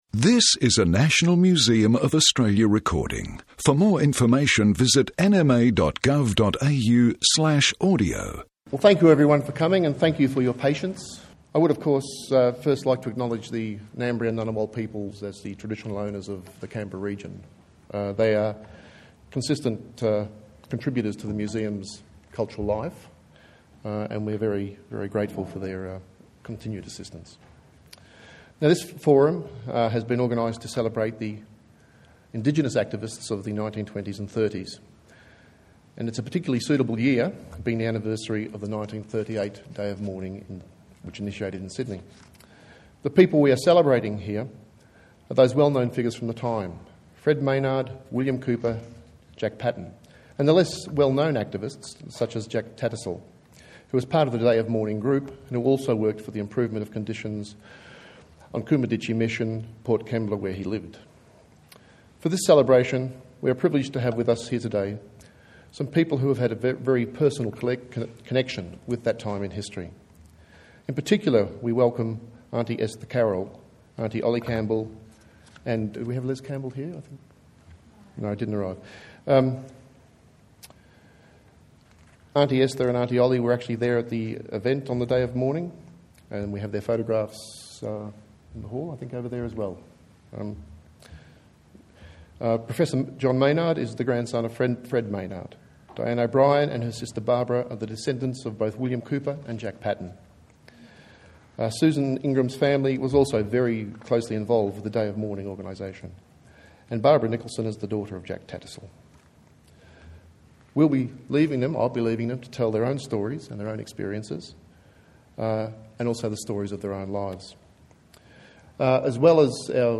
informal discussion